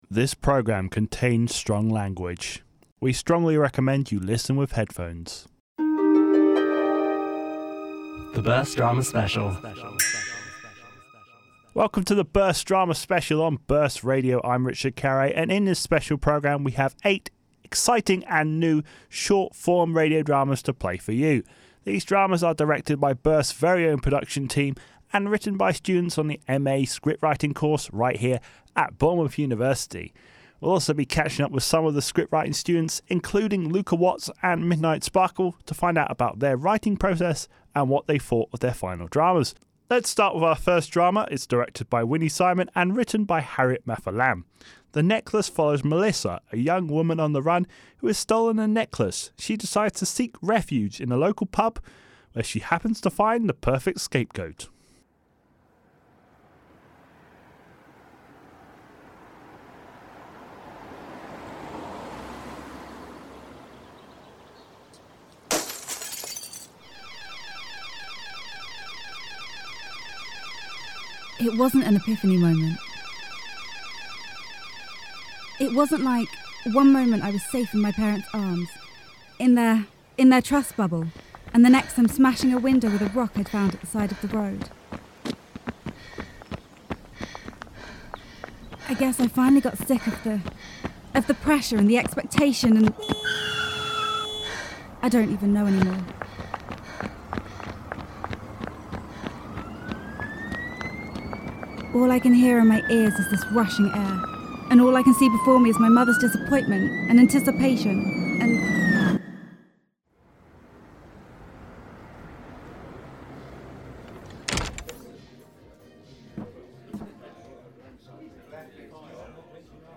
DISCLAIMER: This programme contains strong language. For the best experience, listen with headphones.
The BIRSt Drama Special showcases eight new, 3-5 minute radio dramas produced and directed by our radio production team at BIRSt and written by students on the MA Scriptwriting course at Bournemouth University.